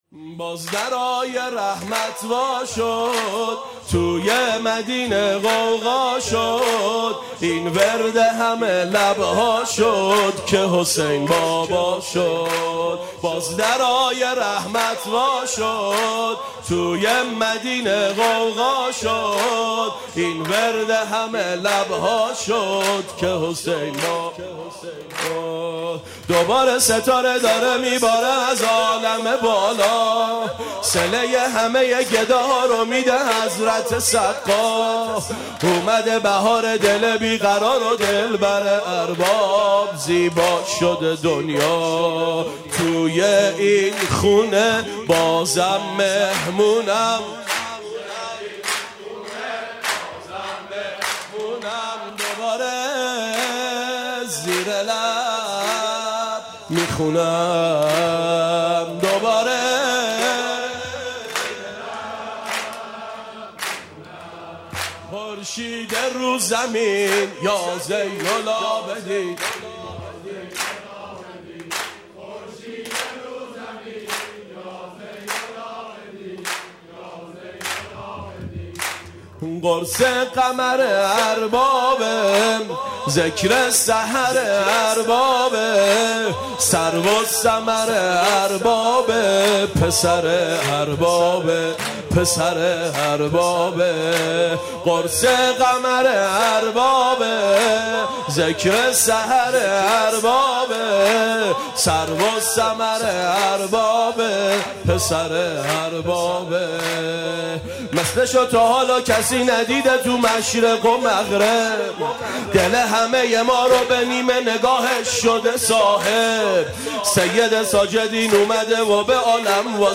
سرود باز درهای رحمت خدا باز شد
میلاد امام سجاد علیه السلام؛ ۱۶ اسفند ۴۰۰